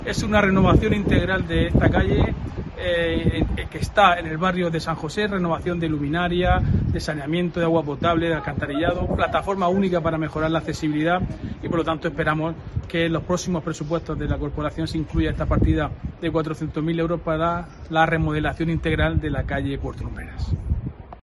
Diego José Mateos, portavoz del PSOE